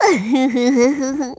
Worms speechbanks
Missed.wav